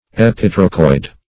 Search Result for " epitrochoid" : The Collaborative International Dictionary of English v.0.48: Epitrochoid \Ep`i*tro"choid\, n. [Pref. epi- + Gr.
epitrochoid.mp3